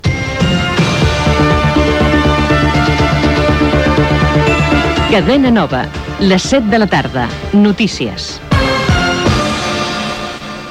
Hora i careta del butlletí de notícies